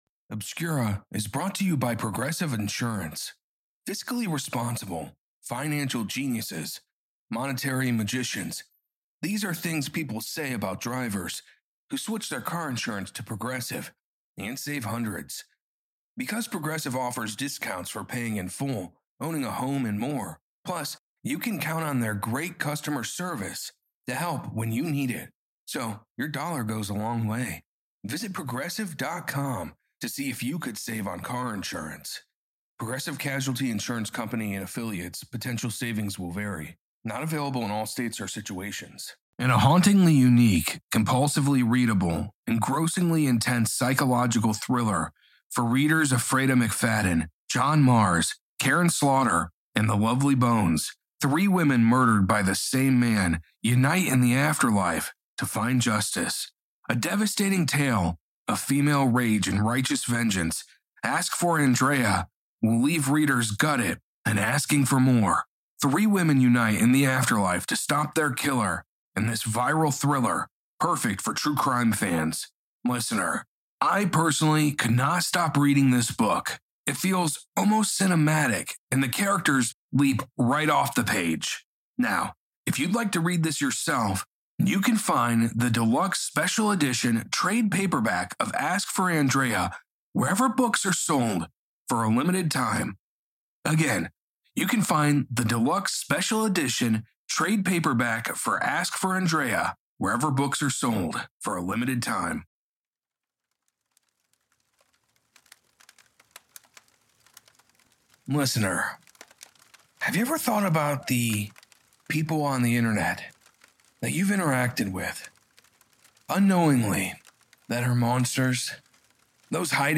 Bernie Sanders Supporters Being ARRESTED at NV Dem Convention 30:39 Play Pause 40m ago 30:39 Play Pause Play later Play later Lists Like Liked 30:39 Live report from NV Democratic convention, Cashman Center. Bernie Sanders supporters working on the credentials committee , removed from committee and issues trespass warnings, under threat of arrest.